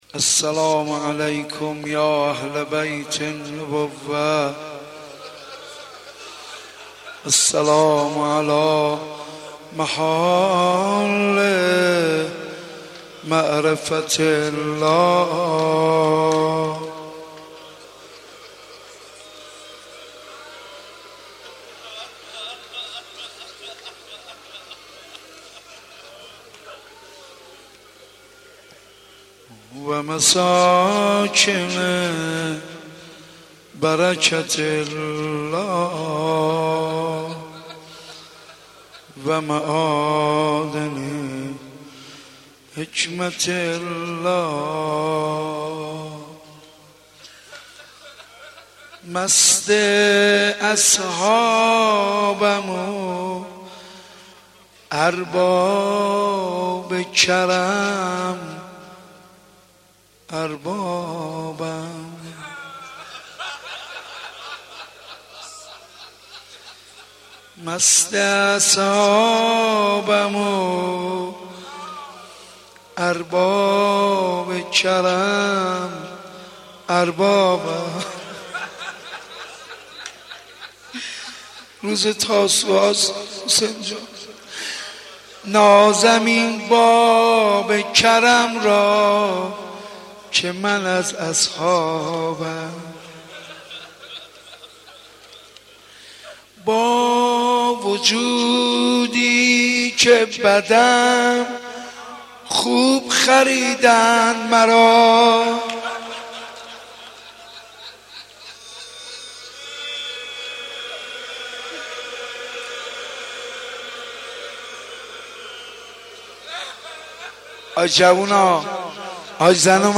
حاج منصور ارضی مداح
مناسبت : تاسوعای حسینی
قالب : مجلس کامل